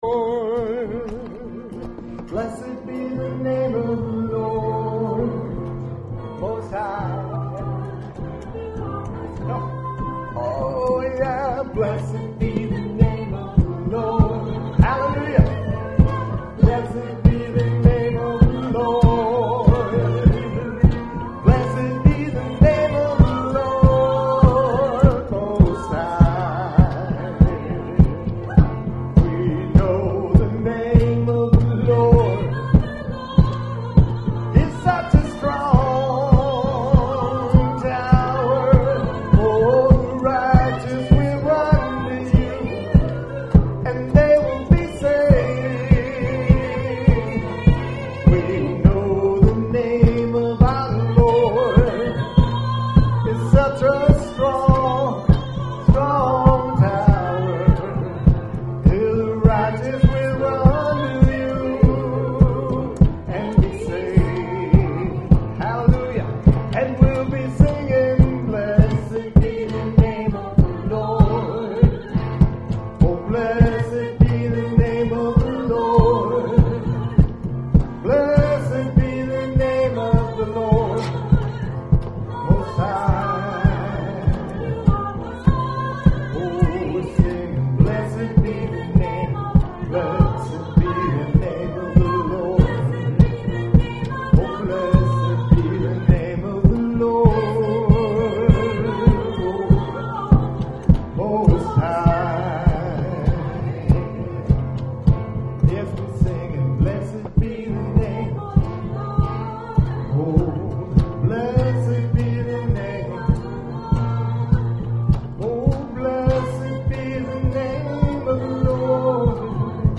WORSHIP1228.mp3